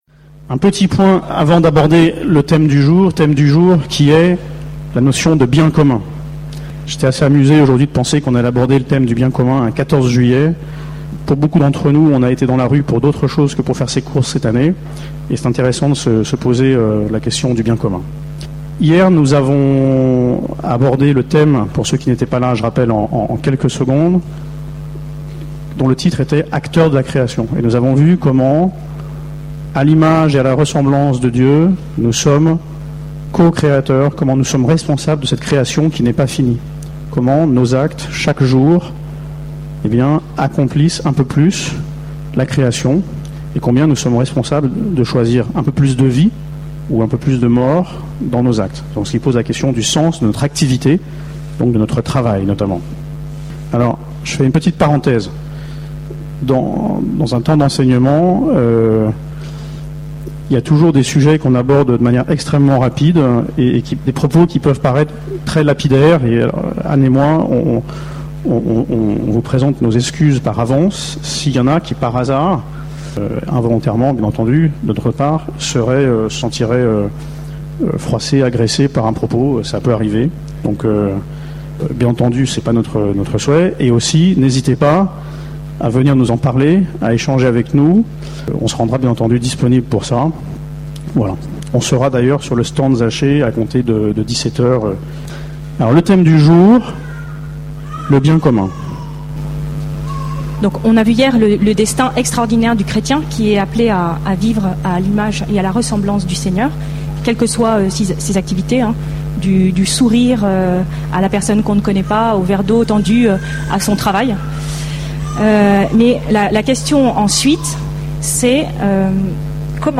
Enseignement
Paray-le-Monial, du 12 au 17 juillet 2013
Format :MP3 64Kbps Mono